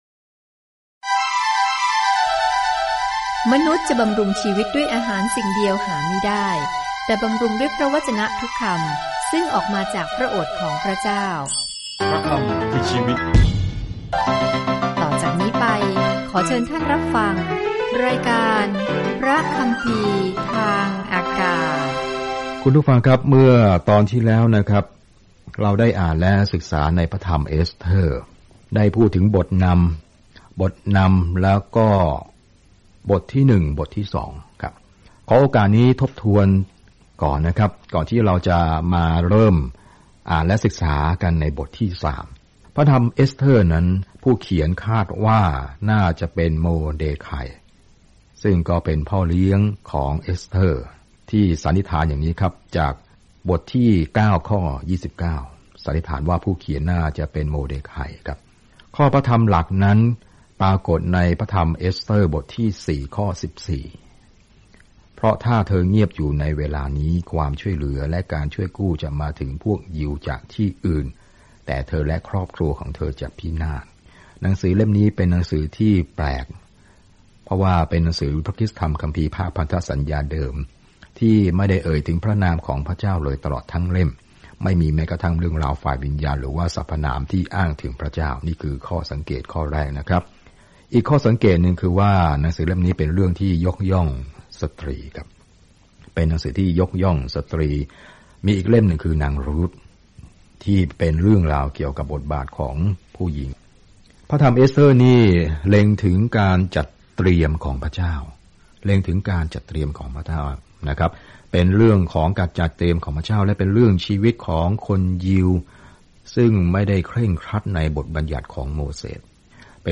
เดินทางผ่านเอสเธอร์ทุกวันในขณะที่คุณฟังการศึกษาด้วยเสียงและอ่านข้อที่เลือกจากพระวจนะของพระเจ้า